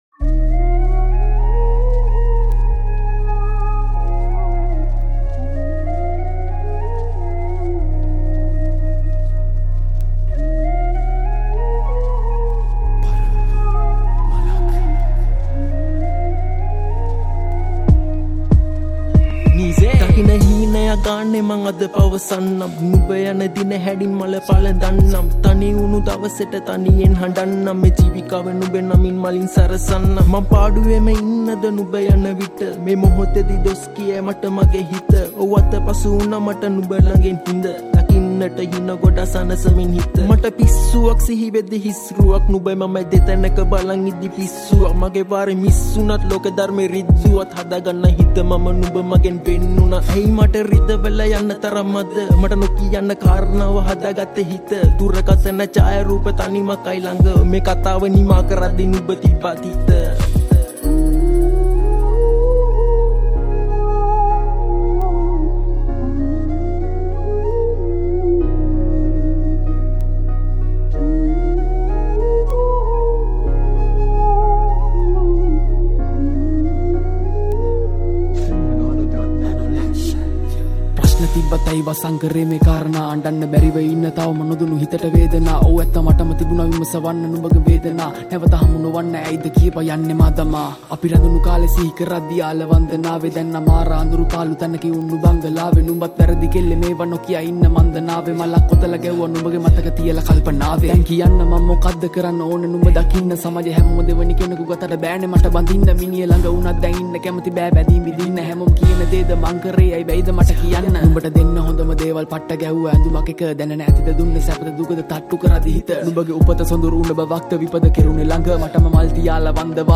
Rap Songs